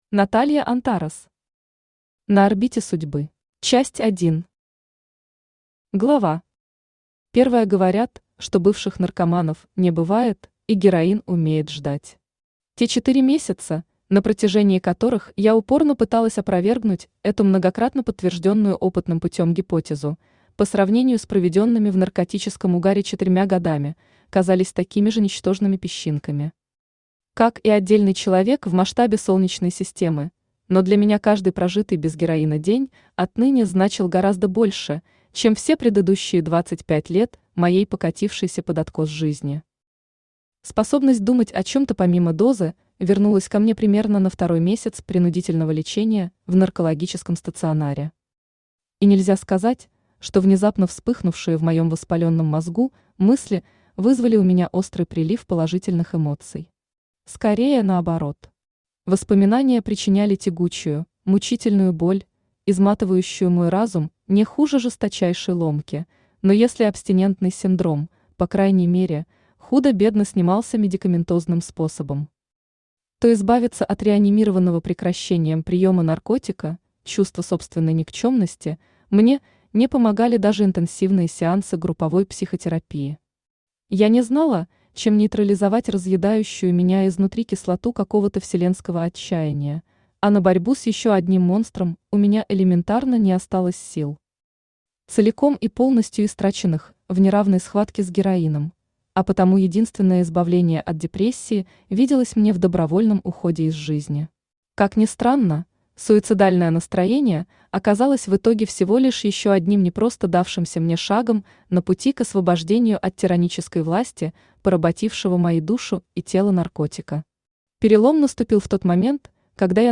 Аудиокнига На орбите судьбы | Библиотека аудиокниг
Aудиокнига На орбите судьбы Автор Наталья Антарес Читает аудиокнигу Авточтец ЛитРес.